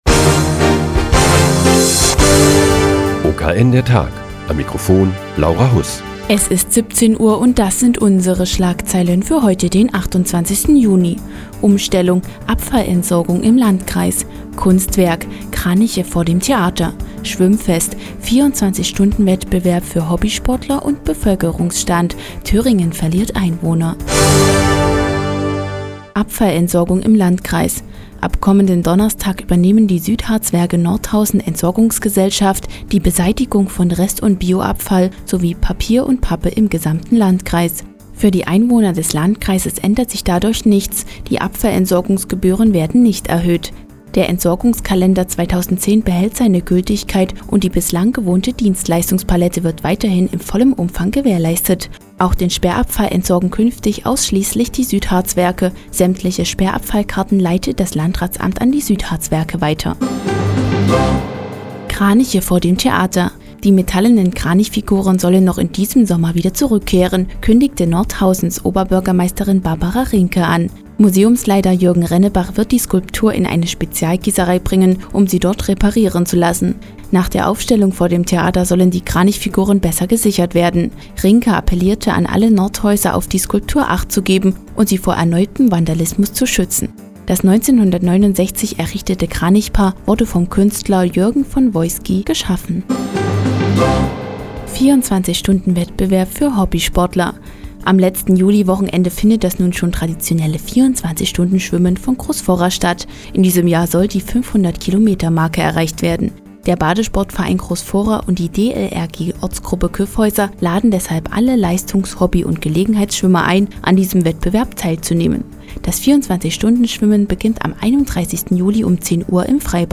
Die tägliche Nachrichtensendung des OKN ist nun auch in der nnz zu hören. Heute geht es um die metallenen Kranichfiguren vor dem Nordhäuser Theater und das 24 Stunden- Schwimmen im Freibad in Großfurra.